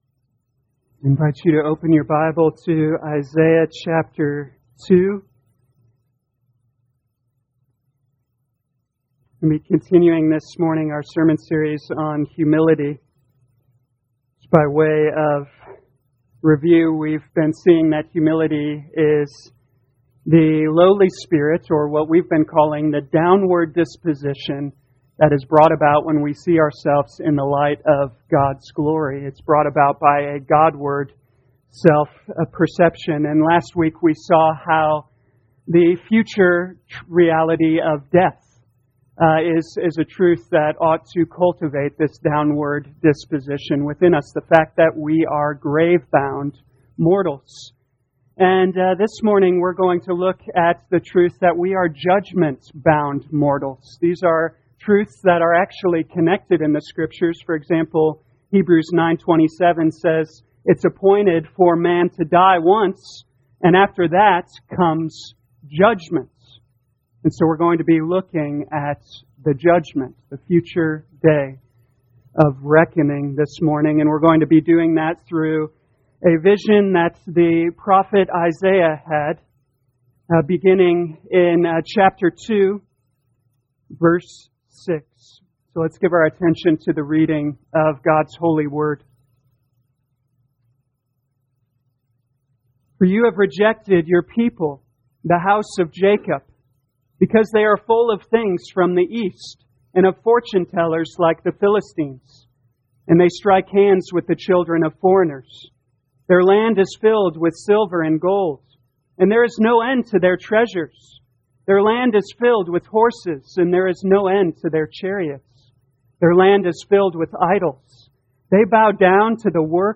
2021 Isaiah Humility Morning Service Download